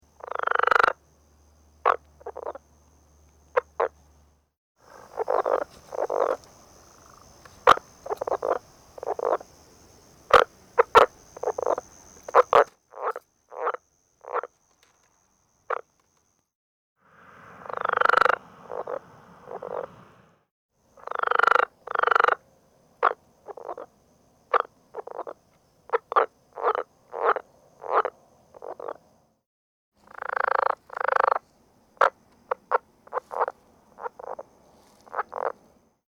Rio Grande Leopard Frog - Lithobates berlandieri
The advertisement call of the Rio Grande Leopard Frog is a loud, short, low-pitched trill or rattle, lasting less than a second, given singly or in rapid sequences of 2 - 3 trills, made at night. Chuckling sounds are also heard along with the trills.
The following advertisement calls were recorded at night at an irrigation canal in Imperial County (shown below right.)
Sound This is a 36 second recording of the sounds of one male frog.
rberlandieri509soloseries.mp3